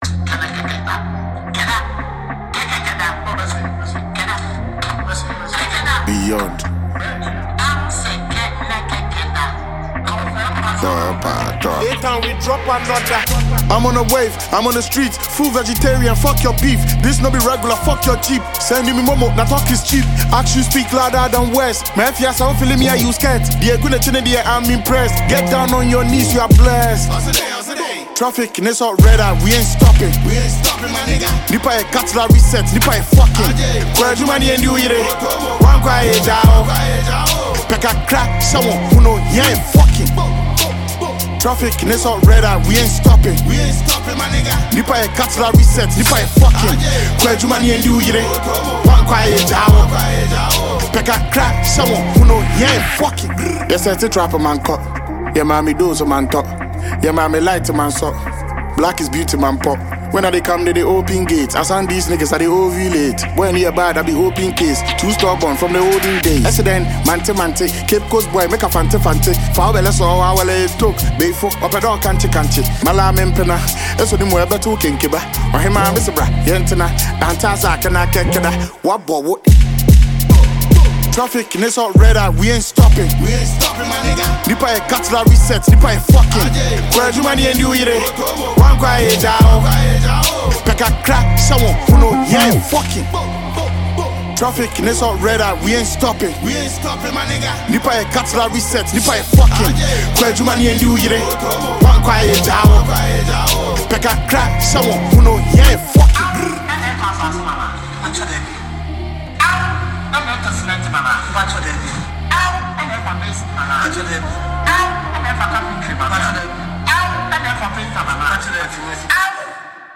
Enjoy a new tune from Ghanaian rapper